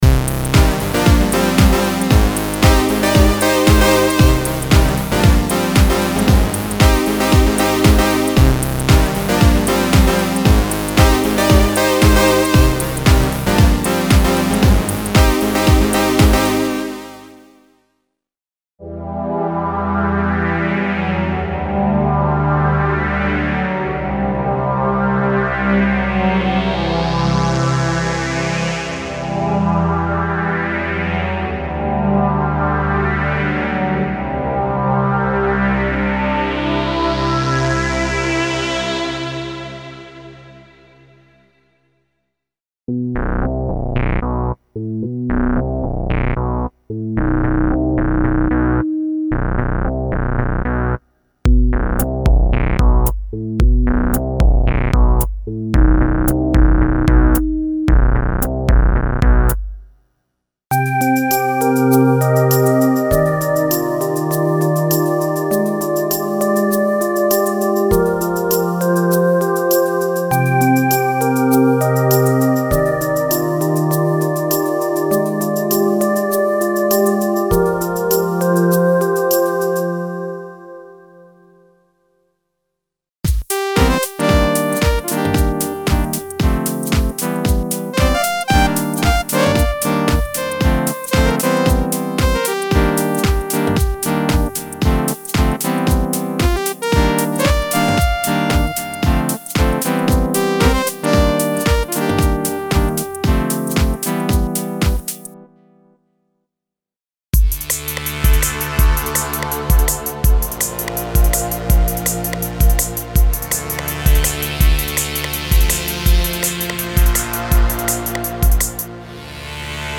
Exclusive collection of professional sound programs contains vintage synth emulations, synth pads, synth basses, synth leads and synth programs specially designed for using with Kurzweil PC3K internal arpeggiator.
A large collection of warm, lush, sharp and modern “analog & digital” synth pads carefully designed for various music styles.
Synth Leads Selection of monophonic and polyphonic leads with 70s and 80s retro feeling, including contemporary experimental leads.